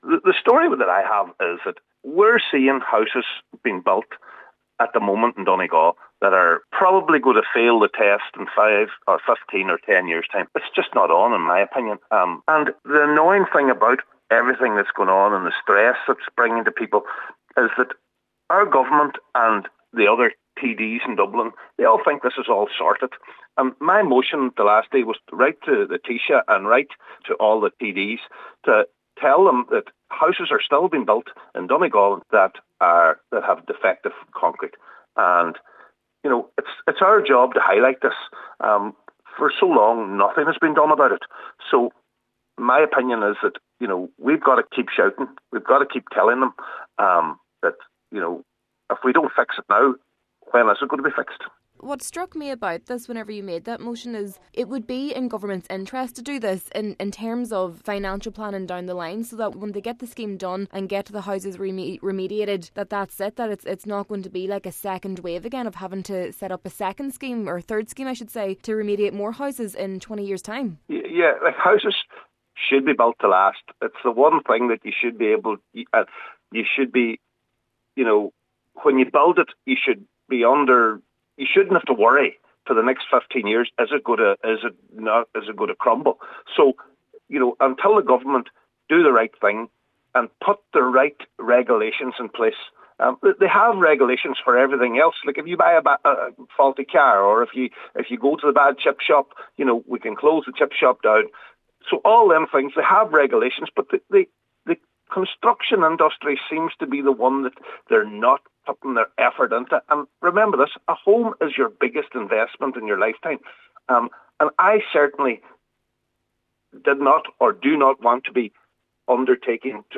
Councillor Farren says it’s a worry for those building houses now that simply should not exists: